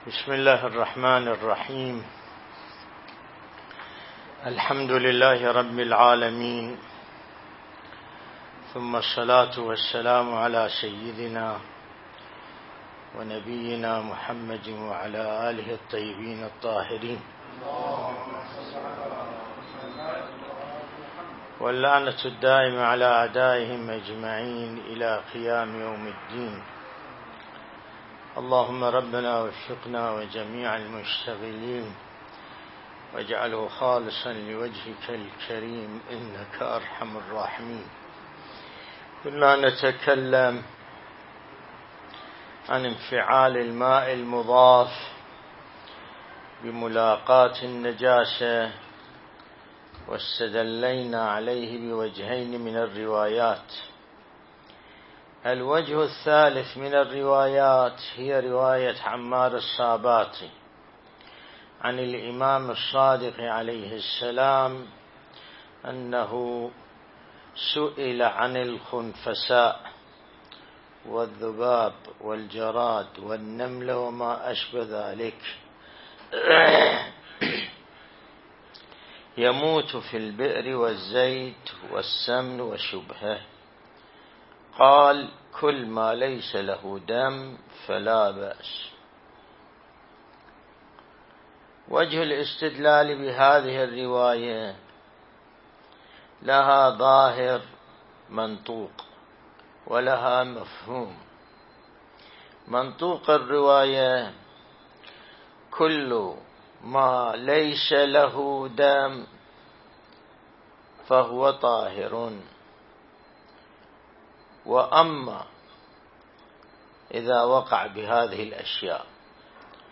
الدرس الاستدلالي شرح بحث الطهارة من كتاب العروة الوثقى لسماحة آية الله السيد ياسين الموسوي (دام ظله)